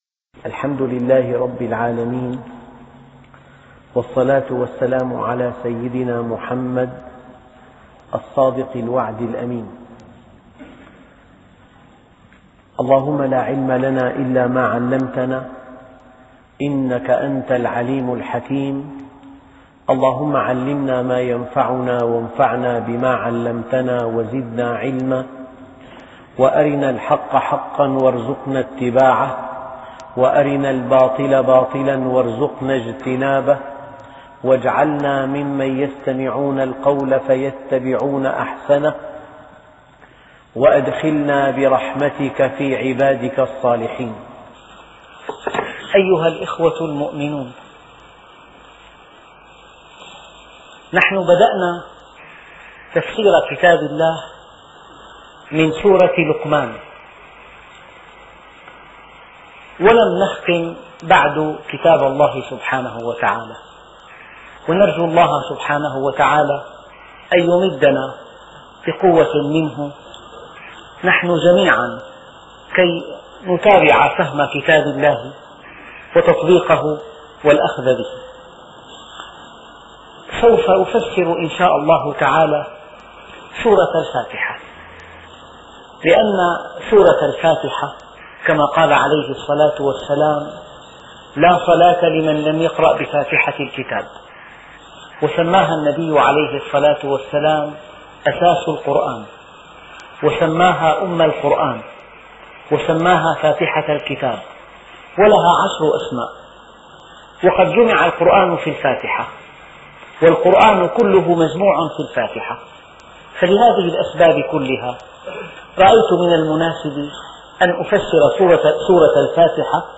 أرشيف الإسلام - ~ أرشيف صوتي لدروس وخطب ومحاضرات د. محمد راتب النابلسي